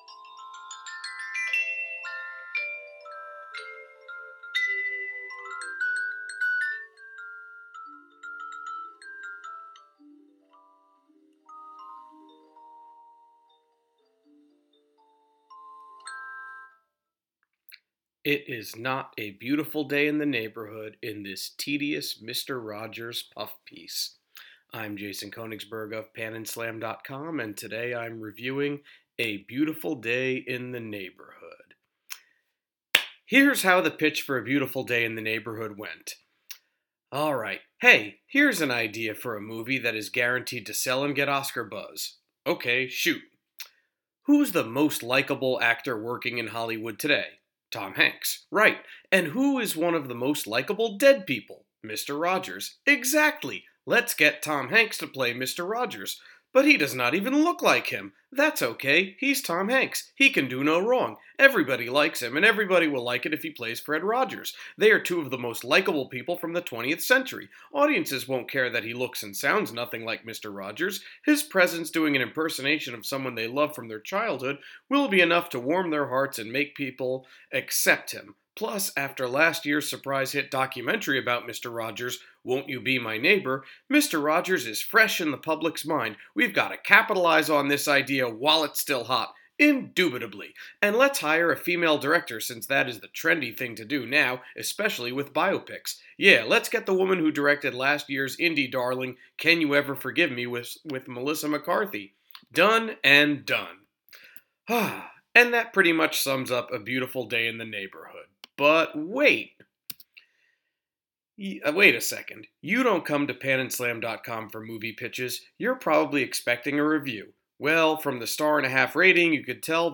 Movie Review: A Beautiful Day in the Neighborhood